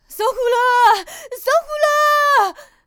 c02_小孩喊着火啦1.wav